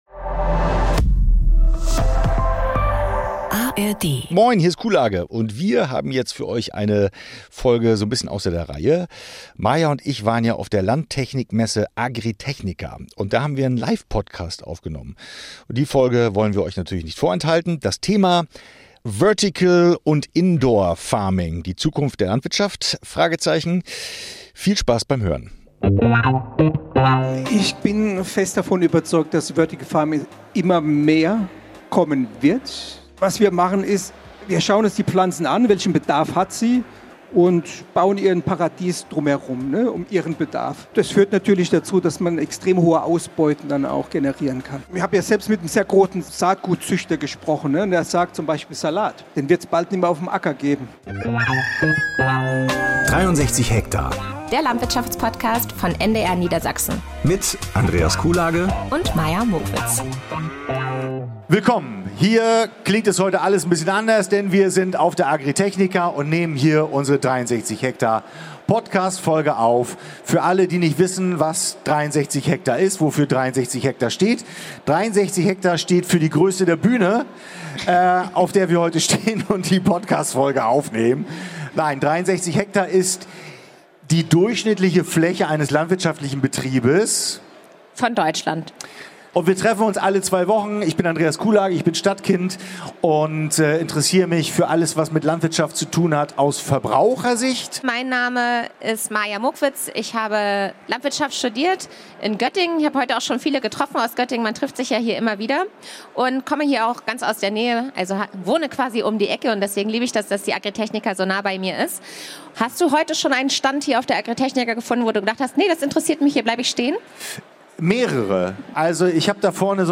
Eine Live-Podcast-Folge direkt von Leitmesse der Landtechnik Agritechnica.